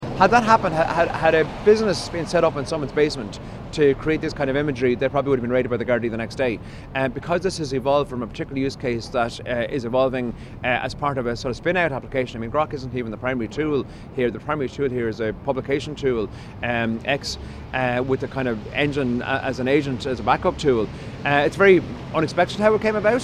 Minister Lawless says it's a complicated issue.